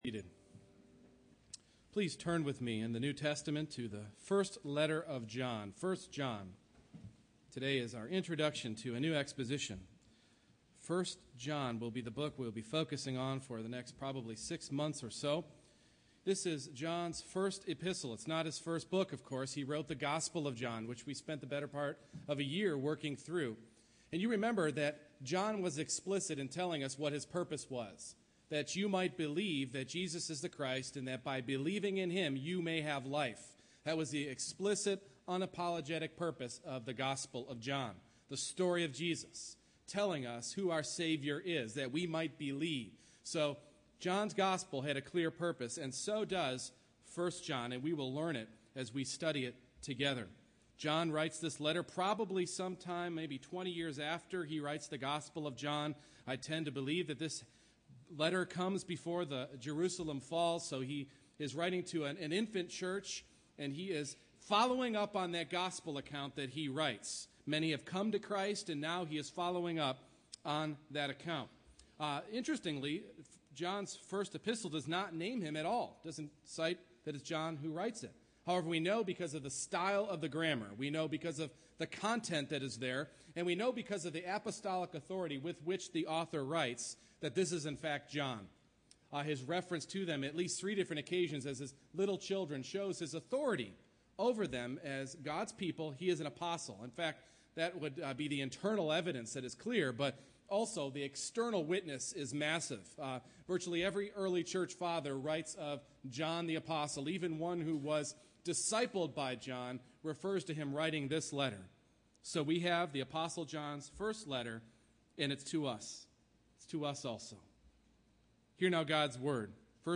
1 John 1:1-4 Service Type: Morning Worship John's epistle helps us to diagnose our spiritual health.